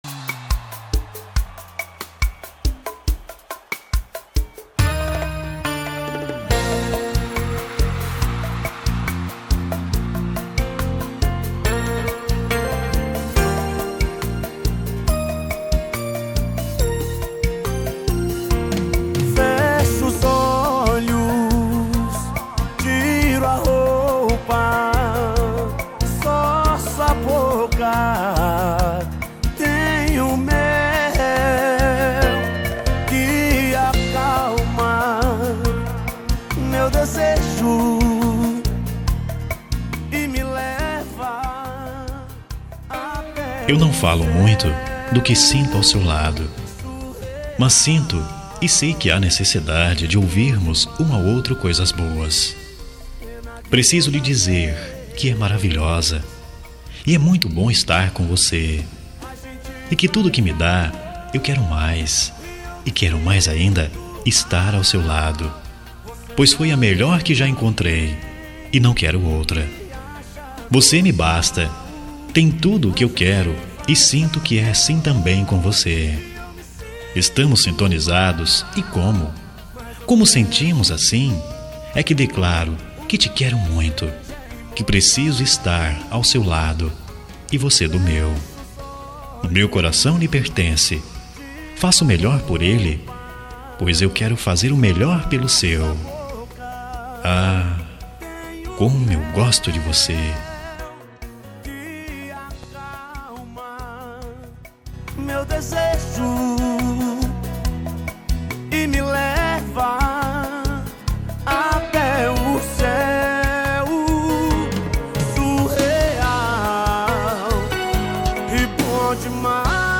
Telemensagem Romântica – Voz Masculina – Cód: 5204 – Linda
5204-romatica-masc.m4a